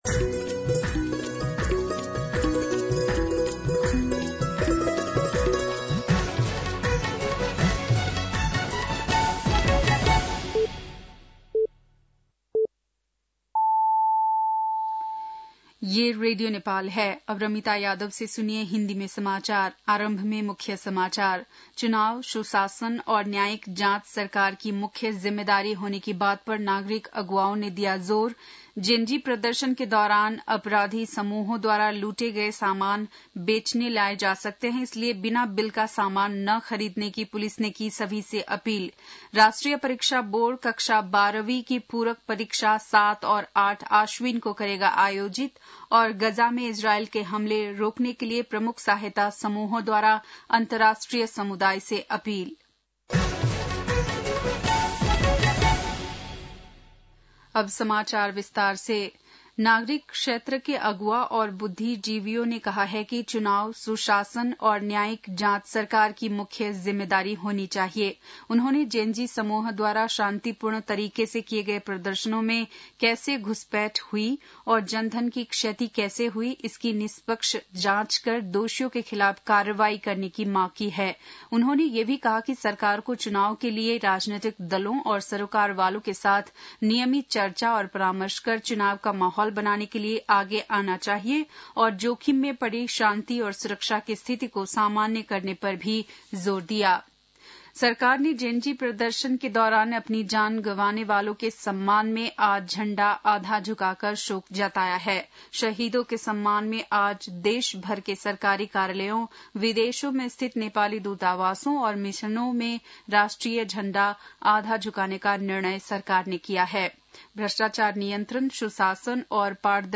बेलुकी १० बजेको हिन्दी समाचार : १ असोज , २०८२
10-pm-hindi-news-6-01.mp3